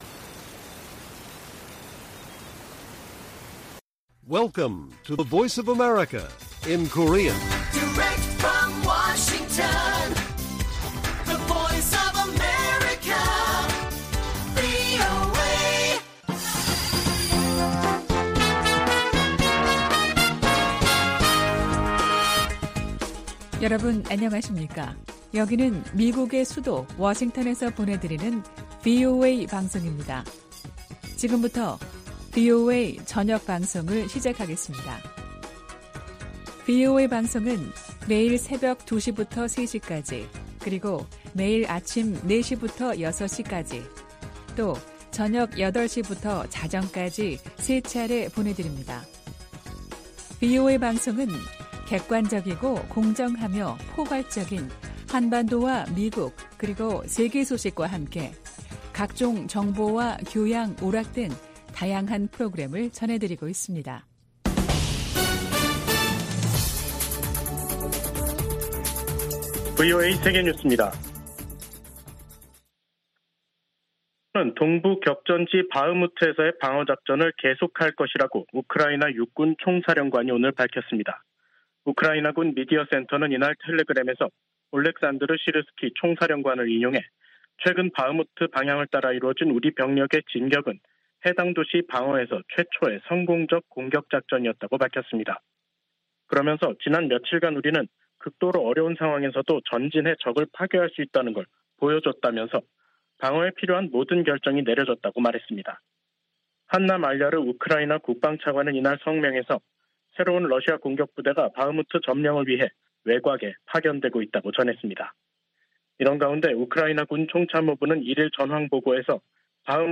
VOA 한국어 간판 뉴스 프로그램 '뉴스 투데이', 2023년 5월 15일 1부 방송입니다. 윤석열 한국 대통령이 일본에서 열리는 G7 정상회의를 계기로 서방 주요국 지도자들과 회담하고 강한 대북 메시지를 낼 것으로 보입니다. G7 정상회의에 참석하는 캐나다와 유럽연합(EU), 독일 정상들이 잇따라 한국을 방문해 윤 대통령과 회담합니다. 북한은 다양한 핵탄두 개발을 위해 추가 핵실험을 하게 될 것이라고 미국의 전문가가 밝혔습니다.